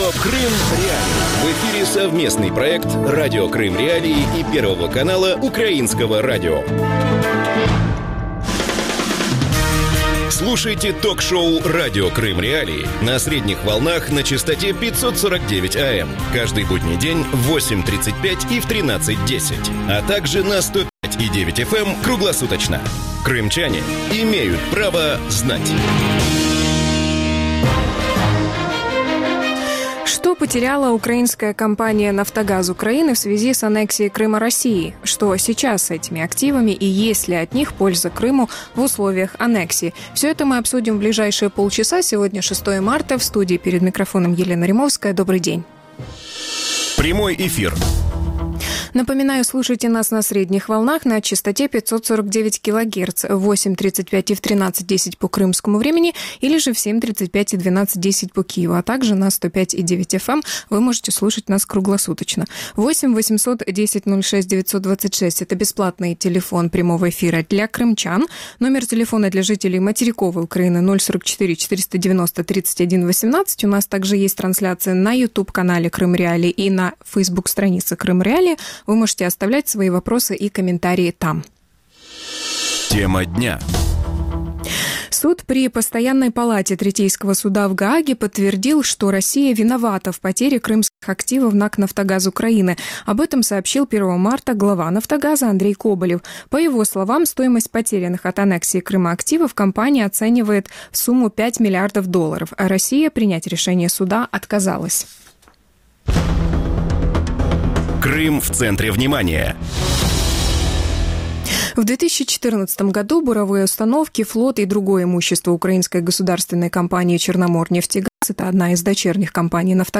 И есть ли от них польза Крыму в условиях аннексии полуострова Россией? Гости эфира